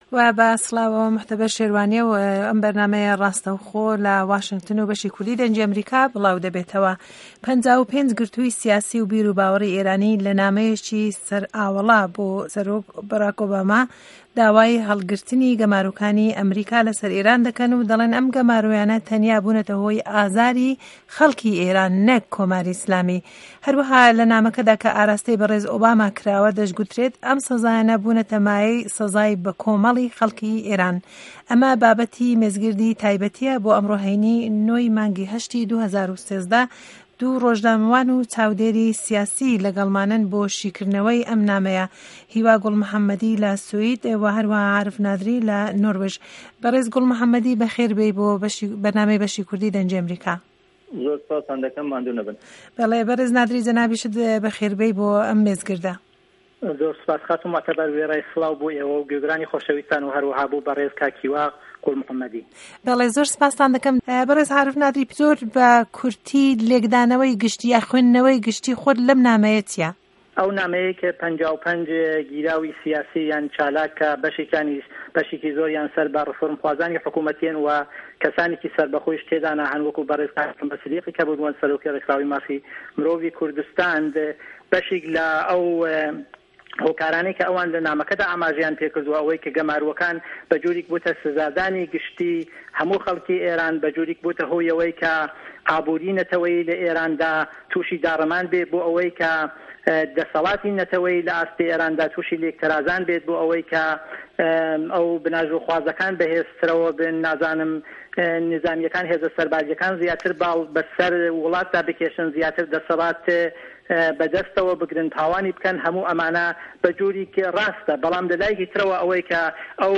مێزگرد له‌سه‌ر نامه‌ی 55 گرتووی سیاسی ئێران بۆ ئۆبام؛ا